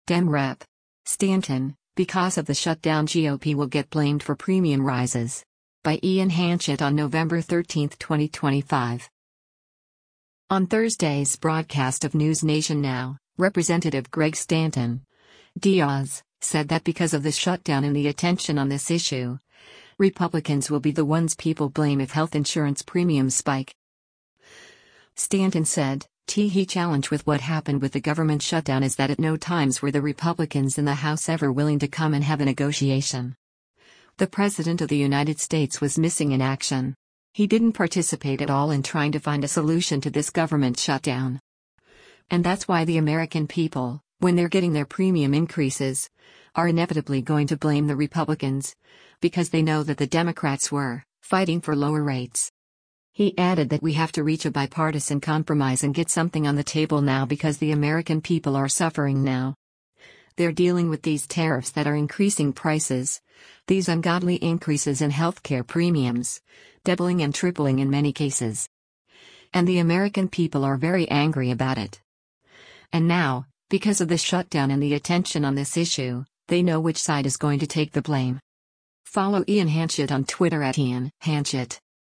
On Thursday’s broadcast of “NewsNation Now,” Rep. Greg Stanton (D-AZ) said that “because of the shutdown and the attention on this issue,” Republicans will be the ones people blame if health insurance premiums spike.